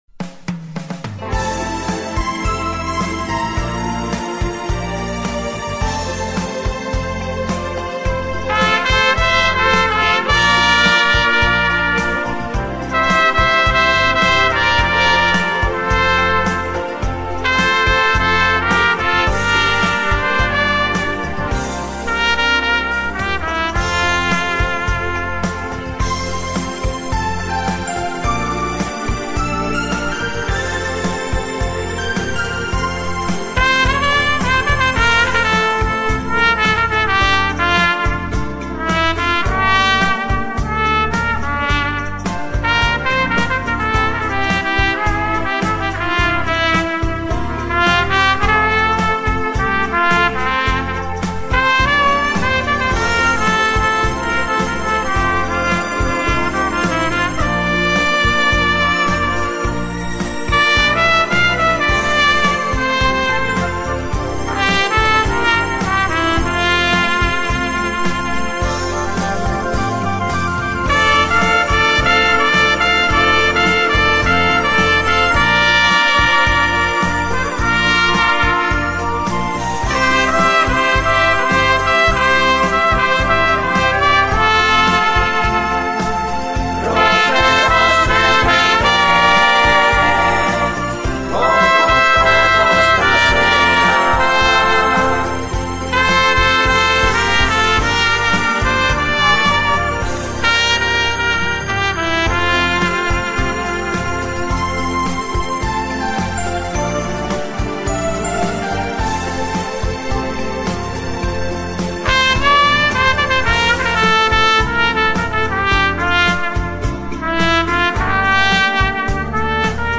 in multiplay recording system